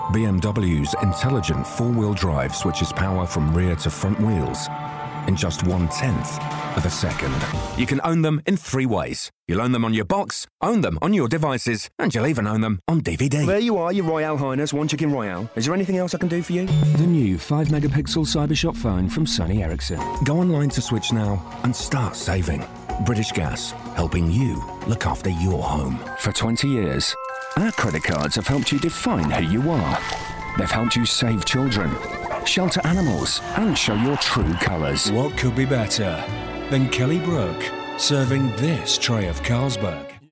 male
Commercial Showreel
Northern, RP ('Received Pronunciation'), Straight
Showreel, Commercial, Cool, Gravitas, Upbeat